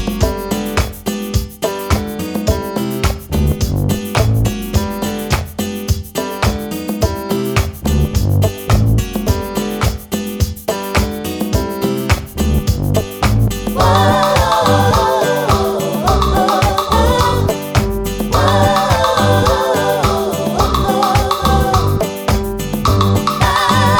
No Backing Vocals Reggae 2:55 Buy £1.50